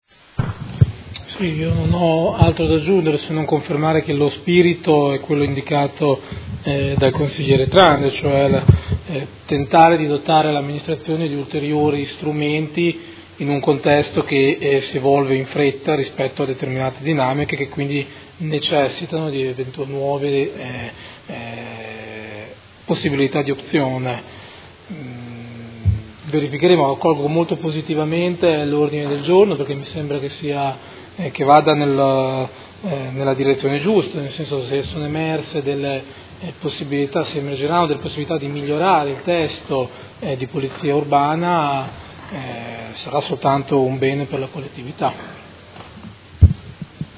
Seduta del 20/07/2017 Conclusioni a Dibattito. Delibera: Modifiche al Regolamento di Polizia Urbana a seguito della L. 48/2017 – Approvazione e Odg n.11949: Regolamento Polizia Urbana